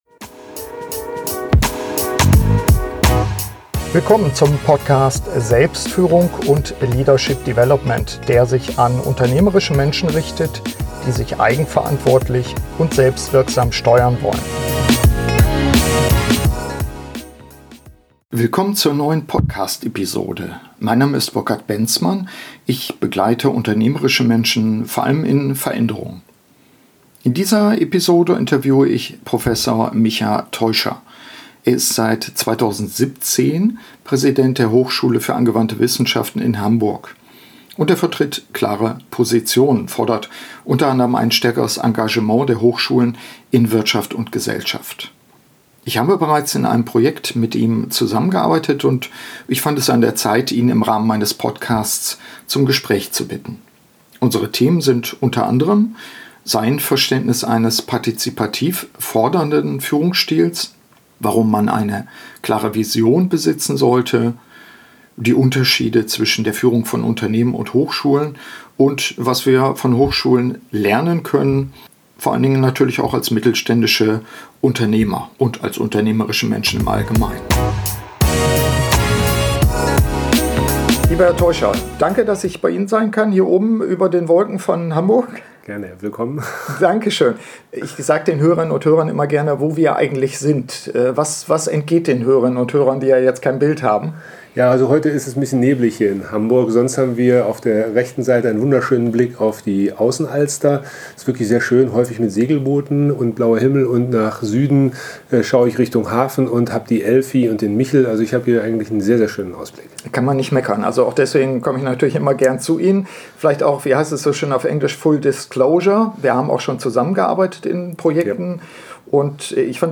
in diesem Podcast-Interview.